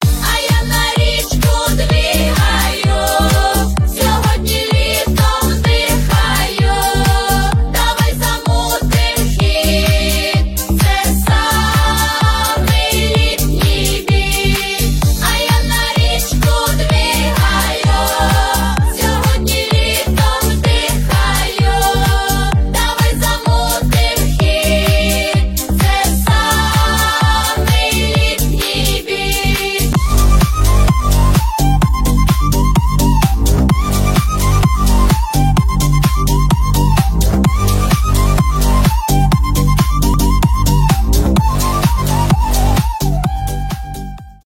танцевальные
позитивные
энергичные
поп